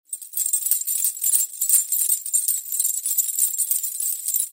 Danza árabe, bailarina mueve las pulseras al bailar 02
agitar
Sonidos: Acciones humanas